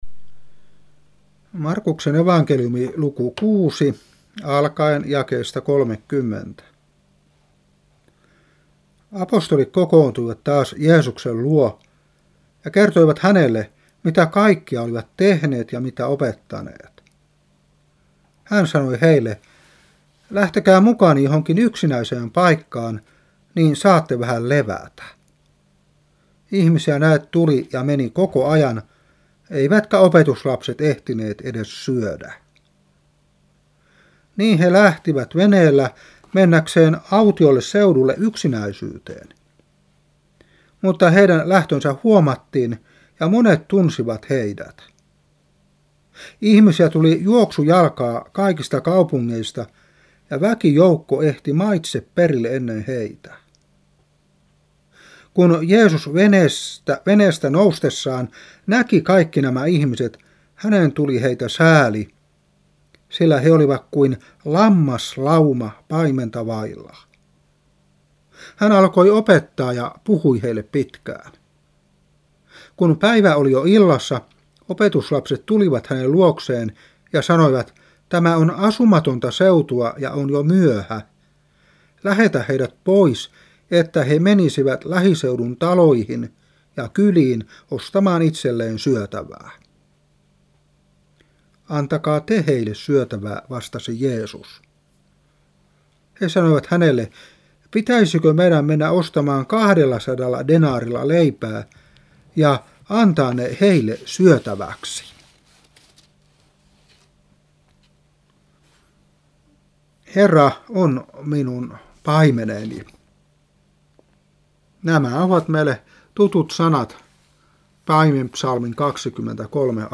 Saarna 1994-4.